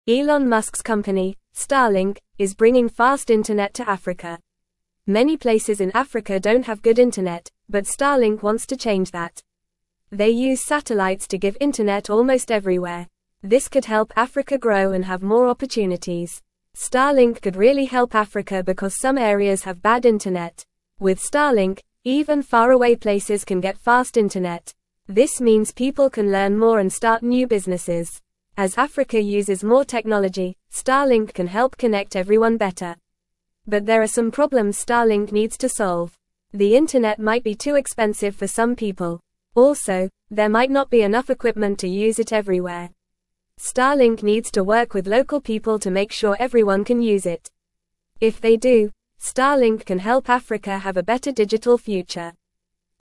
Fast
English-Newsroom-Lower-Intermediate-FAST-Reading-Starlink-brings-fast-internet-to-Africa-to-help-people.mp3